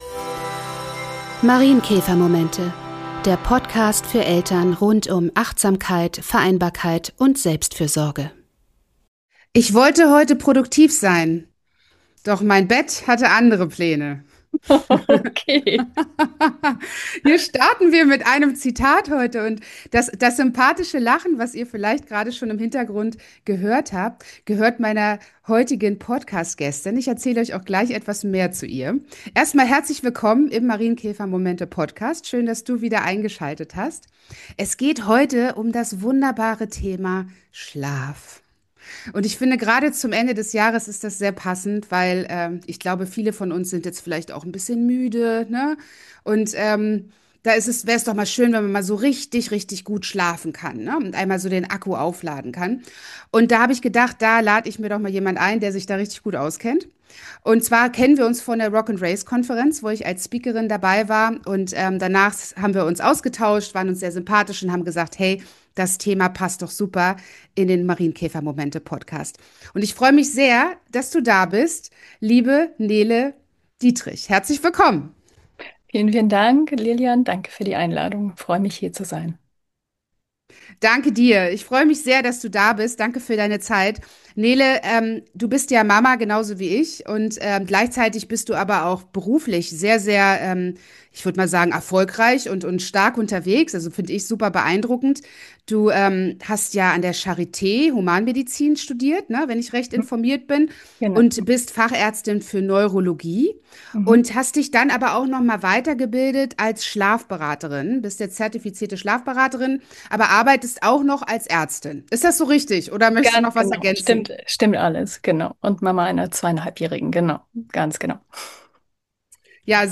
Im Talk mit Schlafcoach